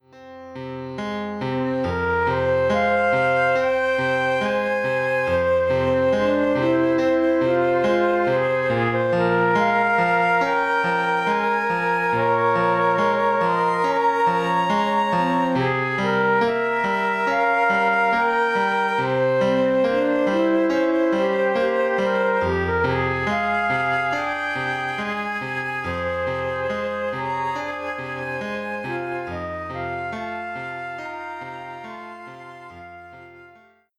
six flutes & piano